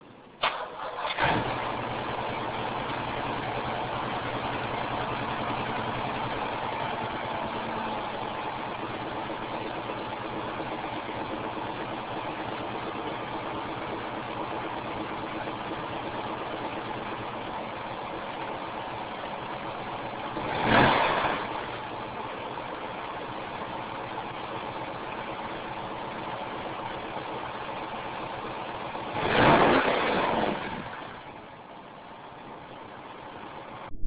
Of course, the Built 327 in the Camaro is a sweet sounding engine too...